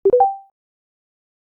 Viber Notification Sound
Viber_Notification_Sound